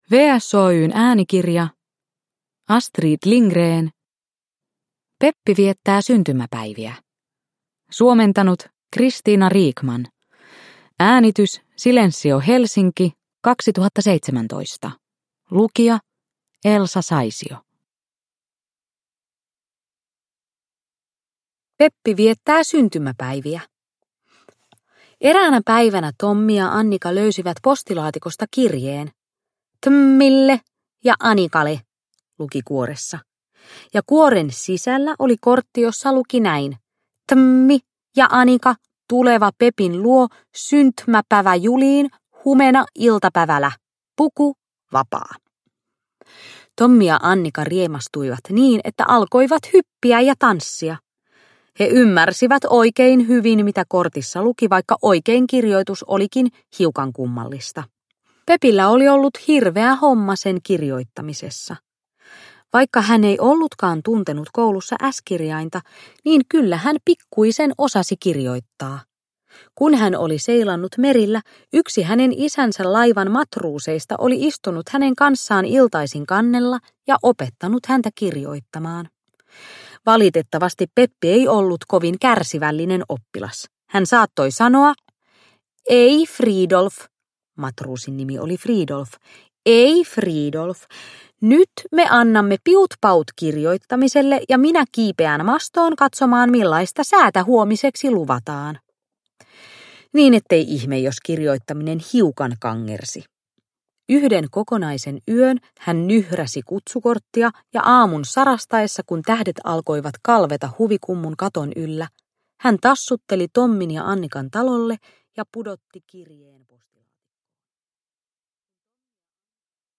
Peppi viettää syntymäpäiviä – Ljudbok – Laddas ner